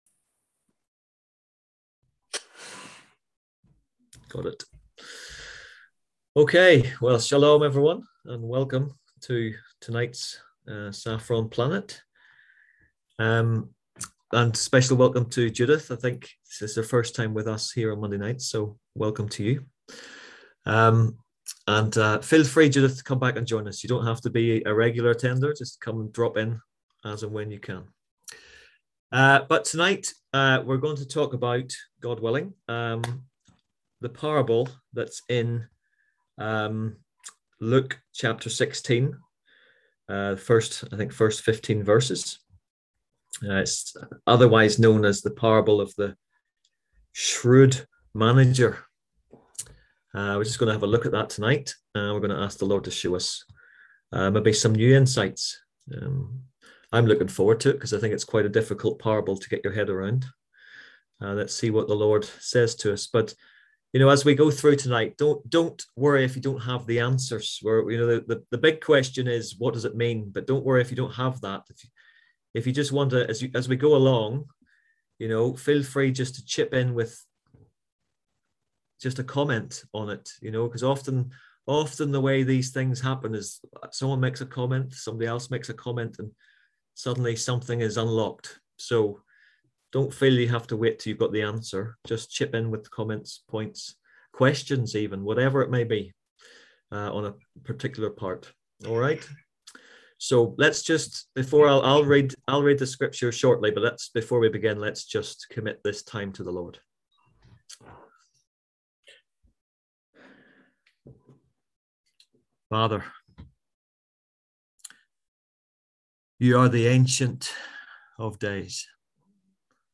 On October 25th at 7pm – 8:30pm on ZOOM ASK A QUESTION – Our lively discussion forum. Tonight’s topic: Parable of the Shrewd Manager CLICK HERE FOR RECORDING [...]
On October 25th at 7pm – 8:30pm on ZOOM